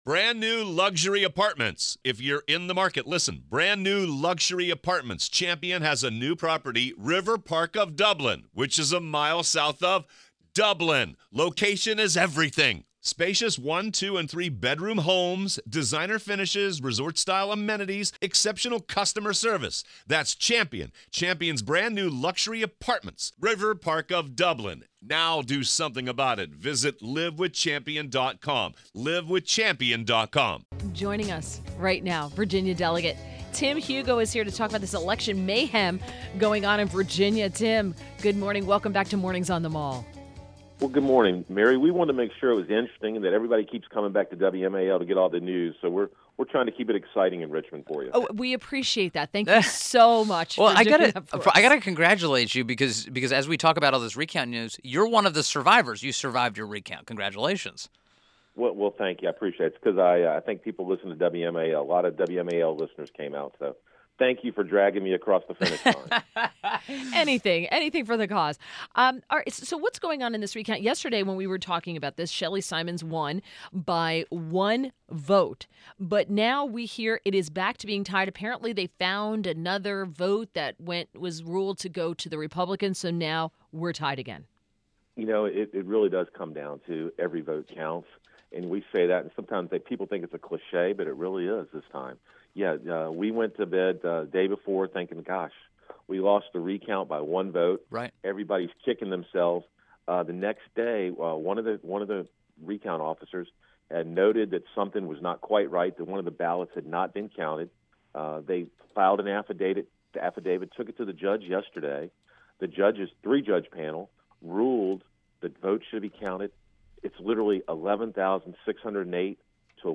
INTERVIEW -- VA DEL. TIM HUGO